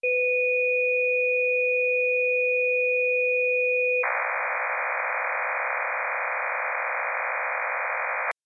Beschreibung 64-Ton Phasenmodulation für störungsfreie
Ein MT63-Signal hört sich wie erhöhtes Rauschen an und ist bei sehr geringen Feldstärken auf Kurzwelle äußerst schwierig zu finden.
MT63-2000S